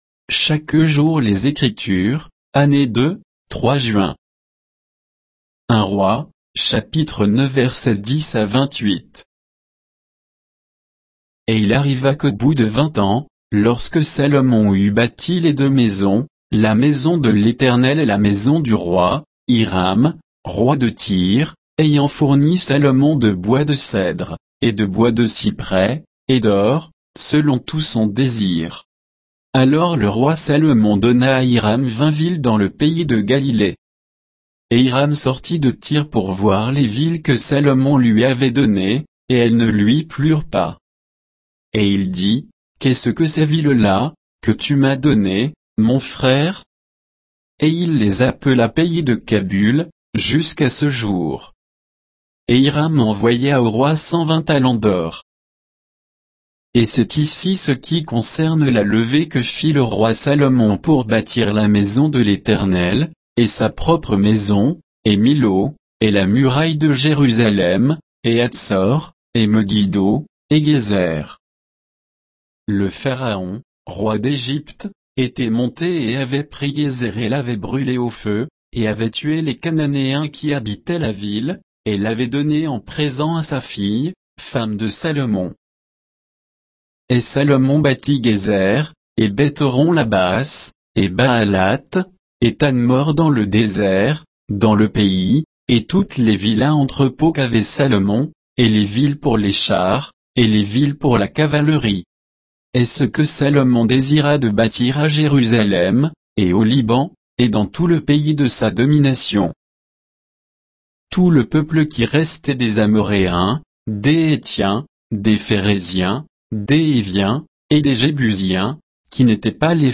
Méditation quoditienne de Chaque jour les Écritures sur 1 Rois 9, 10 à 28